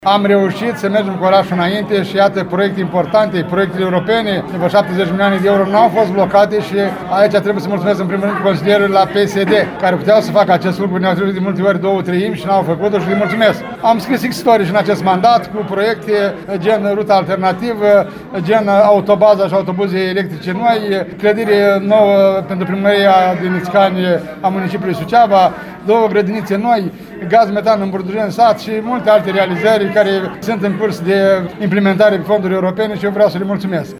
La ultima ședință de Consiliu Local în vechea structură, primarul reales ION LUNGU a mulțumit membrilor legislativului pentru implicarea de care au dat dovadă în promovarea proiectelor pentru dezvoltarea orașului.